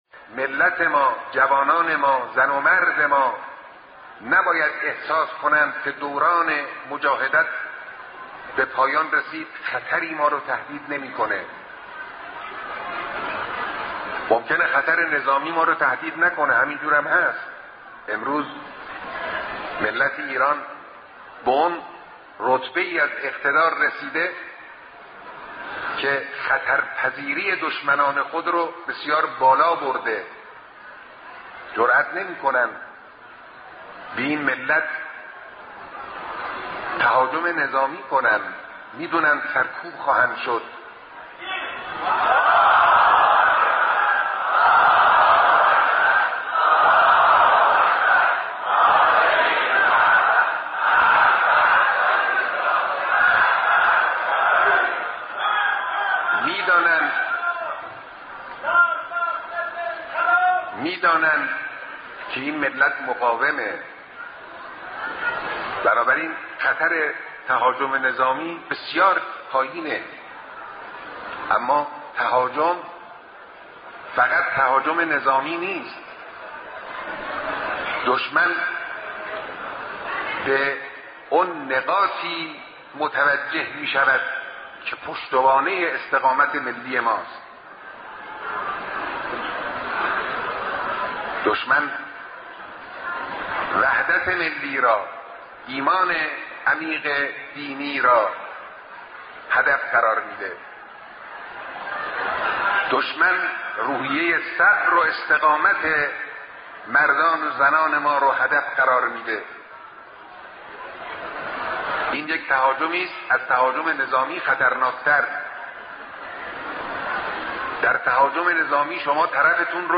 مقتطفات من كلمة الإمام الخامنئي في لقاء مع جمع من الرعيل الأول لقادة ومجاهدي «الدفاع المقدس»
ہفتۂ مقدس دفاع کے موقع پر مقدس دفاع کے کمانڈروں اور سینیئر سپاہیوں سے ملاقات میں تقریر